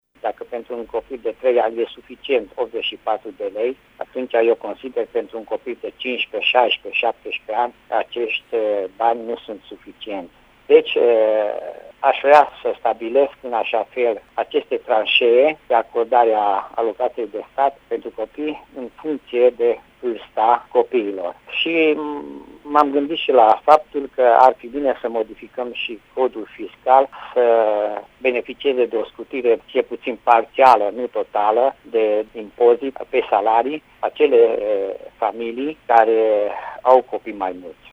În plus, a spus deputatul UDMR, în condiţiile în care demografia în România este în scădere, s-ar impune şi modificarea Codului Fiscal prin acordarea unor facilităţi la impozitarea salariului părinţilor: